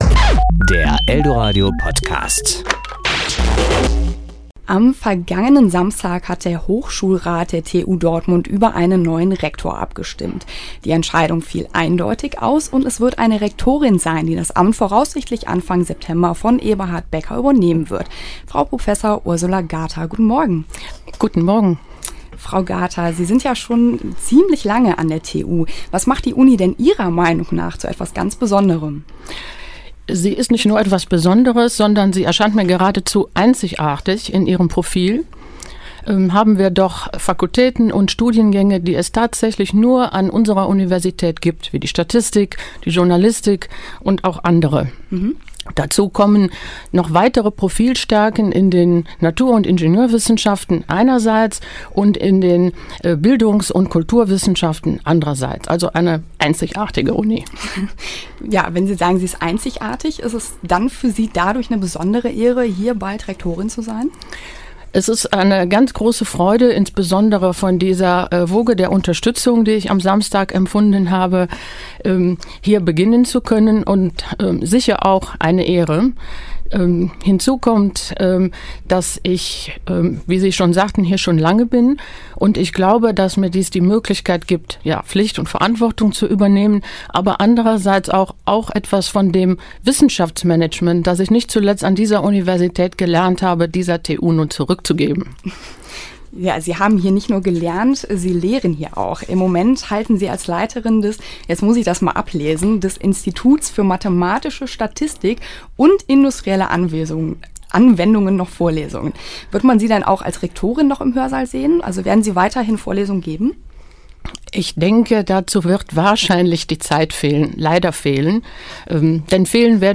Interview mit der neuen TU-Rektorin Ursula Gather